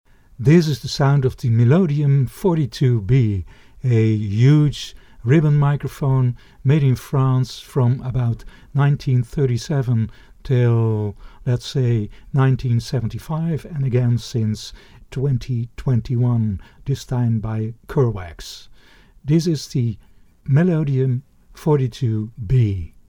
Both microphones have a huge proximity effect; low frequencies are exaggerated when used close to a sound source, when they are moved further away ( 0,5 m or more), the 42B has somewhat more defined lows and more rounded highs.
Music recorded with 42B
Melodium 42B sound UK.mp3